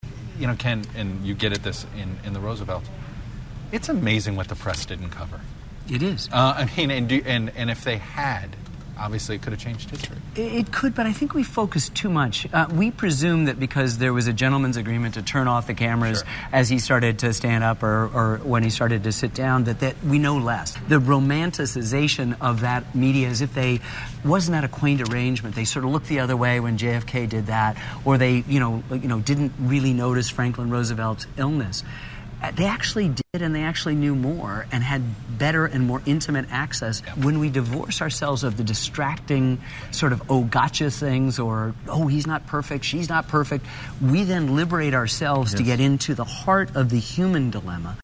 In an interview with Ken Burns on Sunday's web-based Meet the Press feature Press Pass, moderator Chuck Todd asked the historian and film-maker about his PBS documentary on the Roosevelts: "It's amazing what the press didn't cover....I mean, and if they had, obviously it could have changed history."
Here is a transcript of the September 21 Press Pass exchange, which aired on the local NBC Washington D.C. affiliate WRC-4: